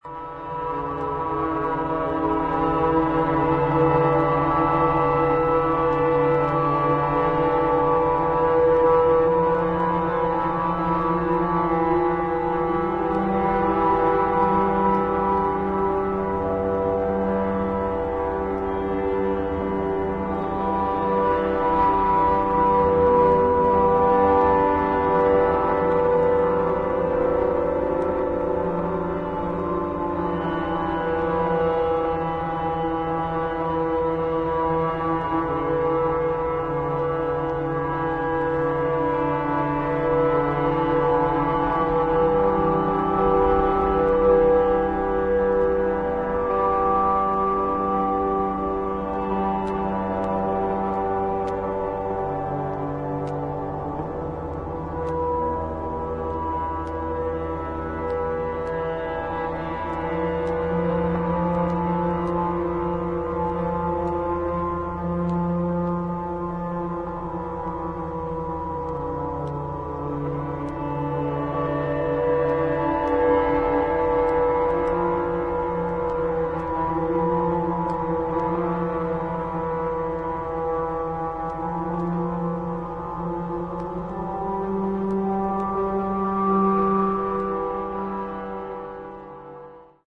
アンビエント感のあるドローン・サウンドが幾重にもレイヤーされ、深みのある重厚な世界観を披露している素晴らしい内容。